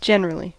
Ääntäminen
IPA : /d͡ʒɛnɹəliː/